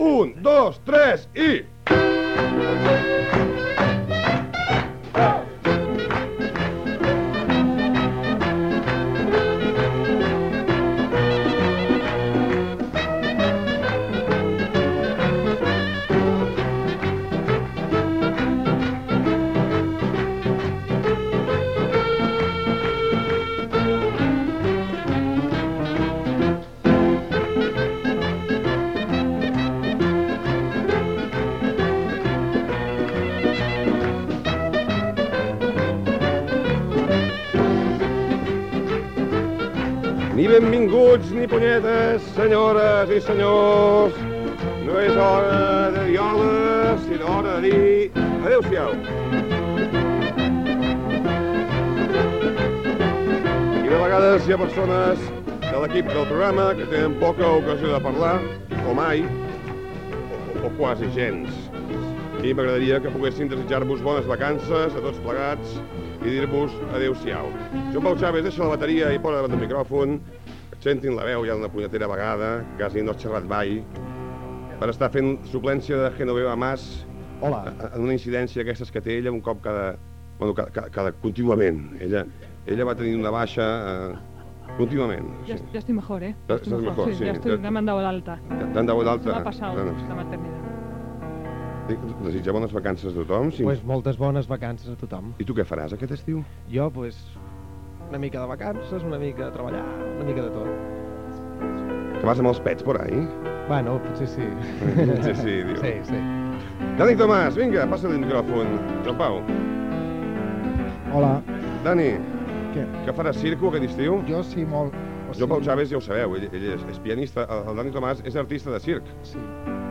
Final de la sèrie de programes emesos la temporada 2000/2001. Música en directe i comiat dels integrants de l'equip
Entreteniment